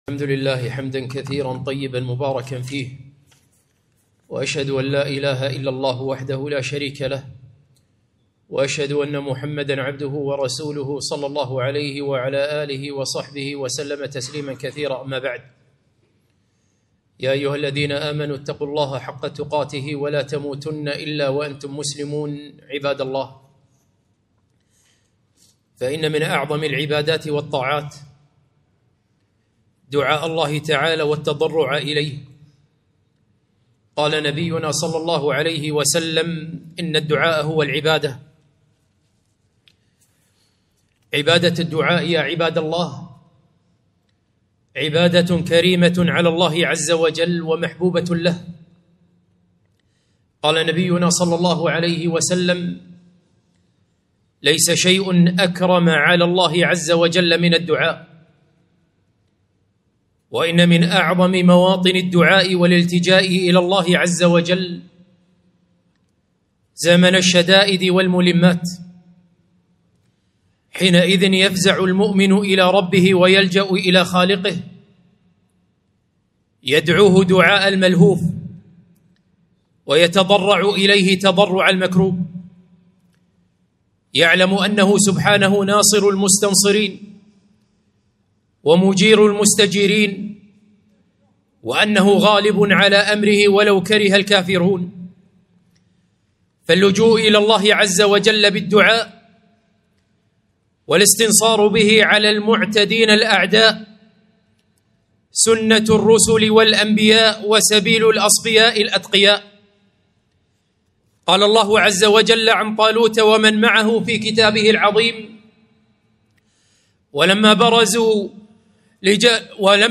خطبة - حال المؤمنين والمنافقين في الأزمات